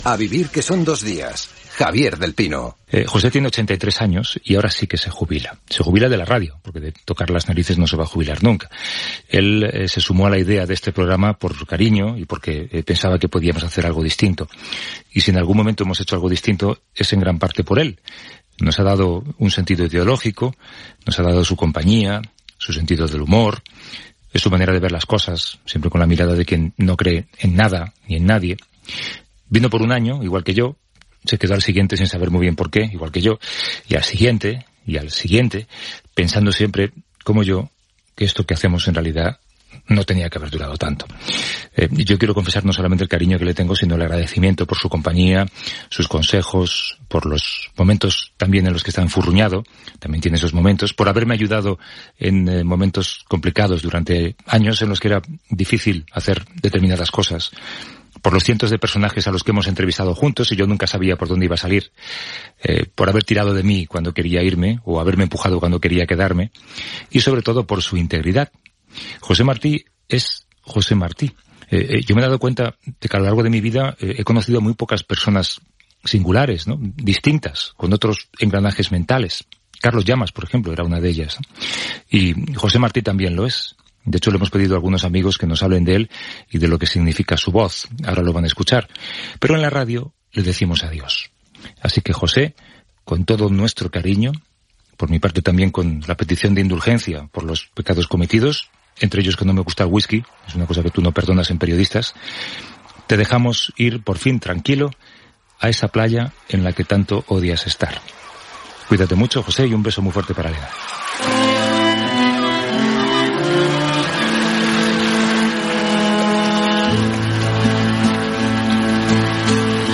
Amb les intervencions de Gemma Nierga, Iñaki Gabilondo i Jordi Évole, entre d'altres, i fragments d'alguns dels seus comentaris radiofònics a la Cadena SER Gènere radiofònic Entreteniment